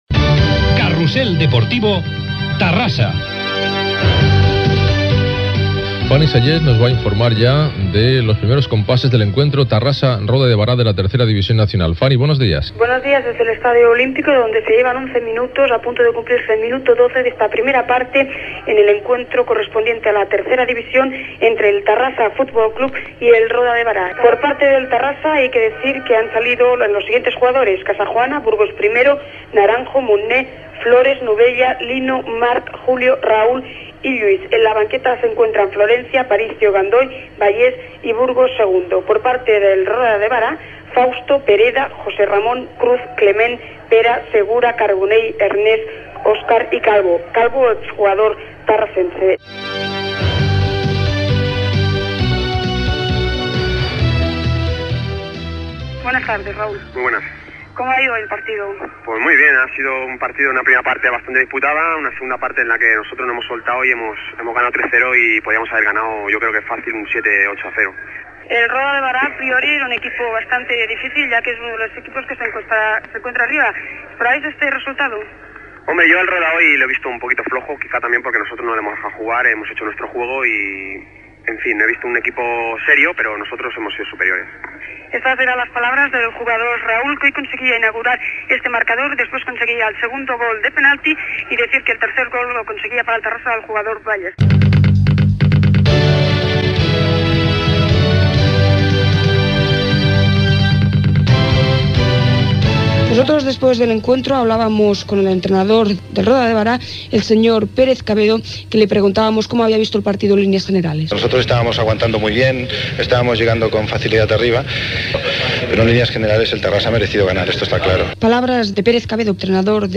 Carrusel Deportivo: transmissió futbol - Ràdio Terrassa, 1993